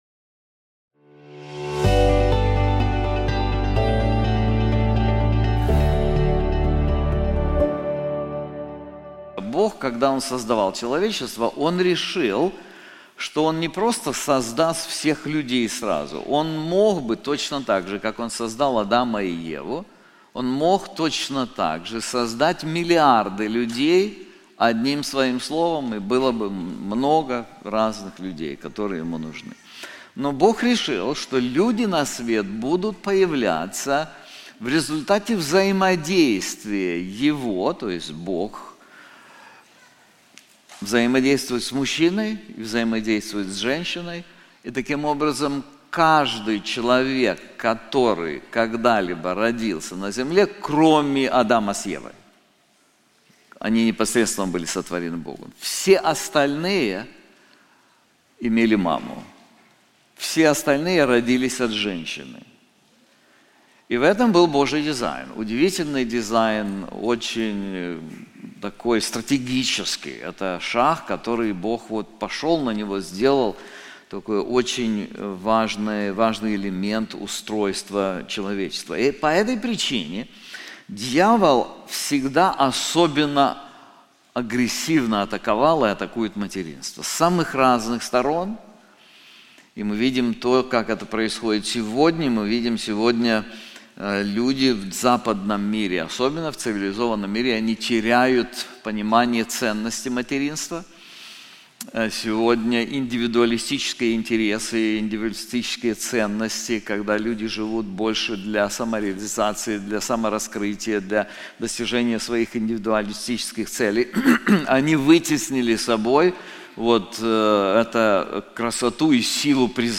This sermon is also available in English:A Mother's Faith • 2 Timothy 1:3-5